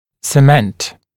[sə’ment] [сэ’мэнт] 1. цемент, цементирующее вещество, пломбировочный цемент; 2. фиксировать на цемент (часто о бандажных кольцах и реже о брекетах)